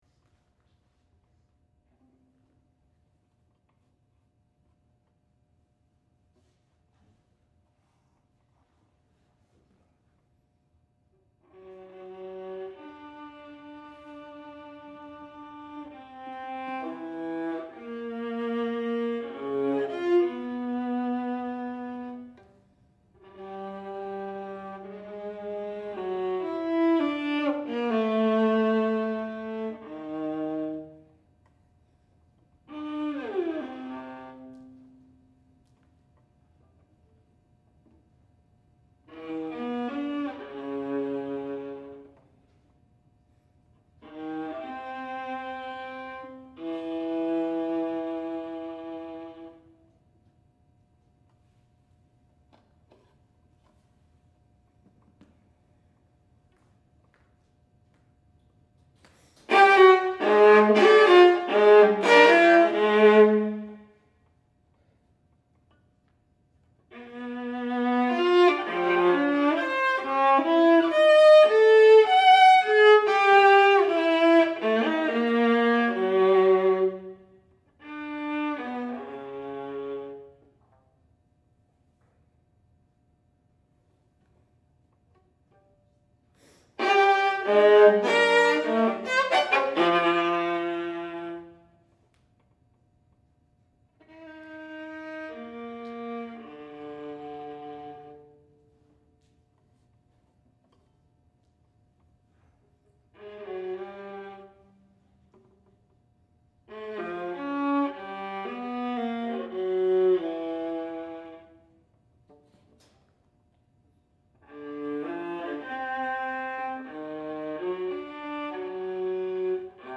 viola solo